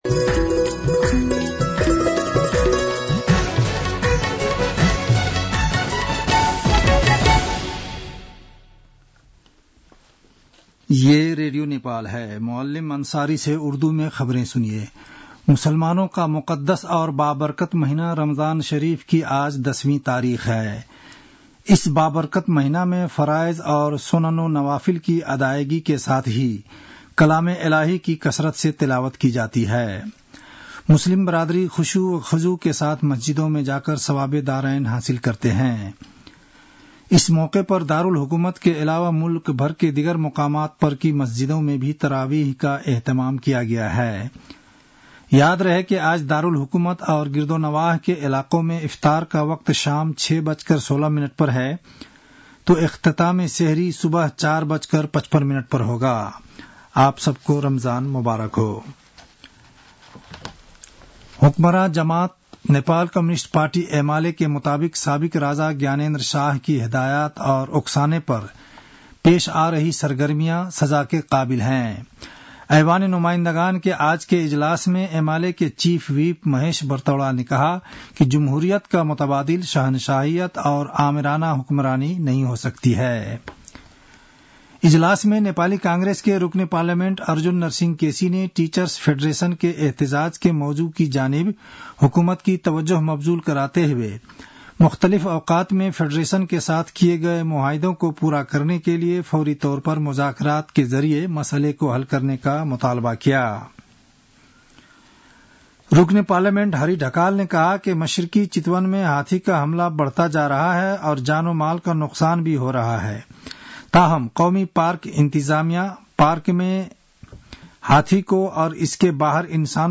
उर्दु भाषामा समाचार : २८ फागुन , २०८१